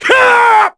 Crow-Vox_10_kr.wav